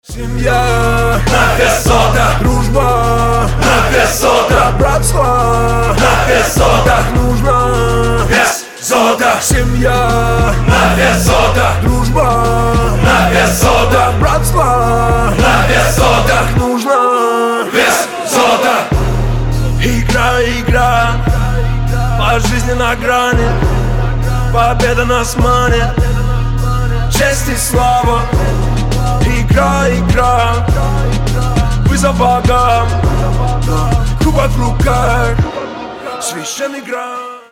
• Качество: 320, Stereo
мужской голос
мотивация